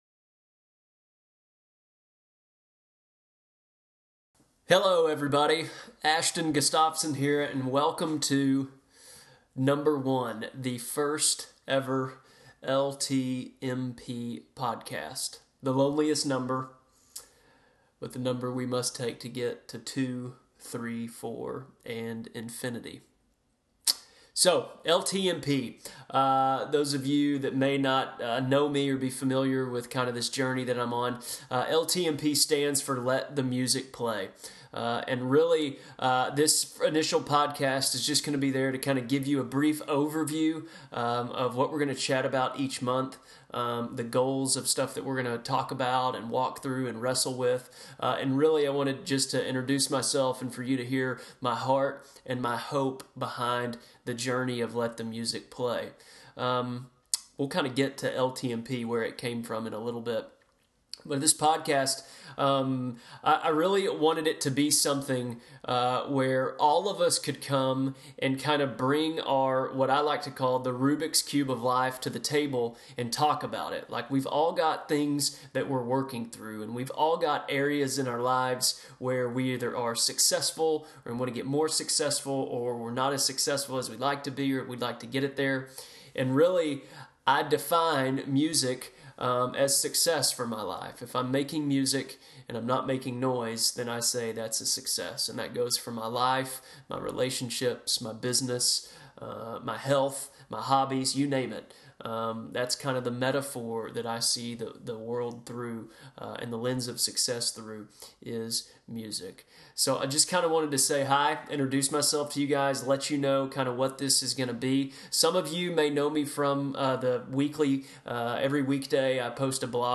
It’s raw.